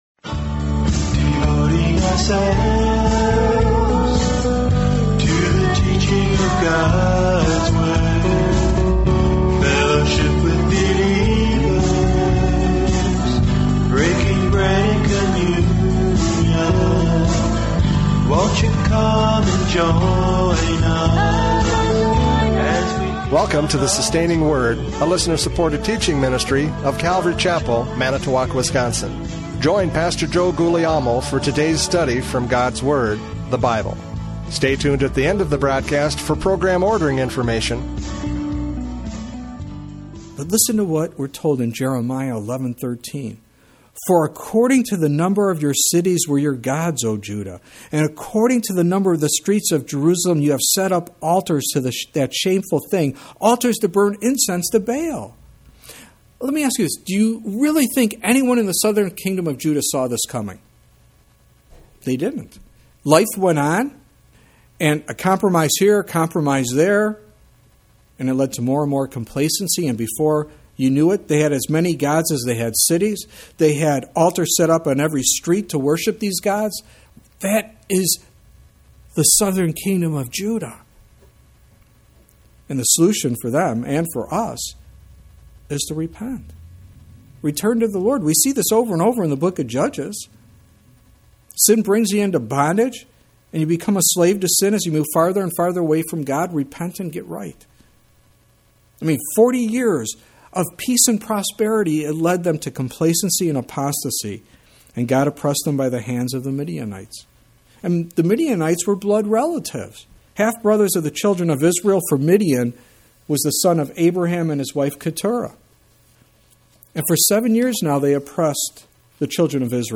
Judges 6:1-5 Service Type: Radio Programs « Judges 6:1-5 Sin Never Profits!